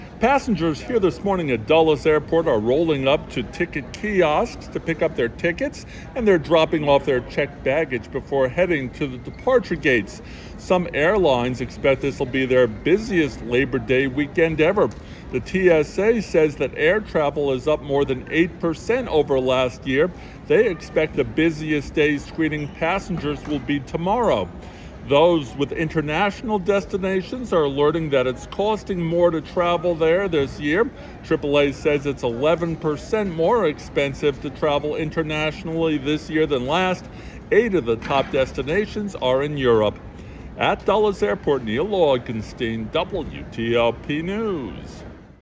2-DULLES-nau-.mp3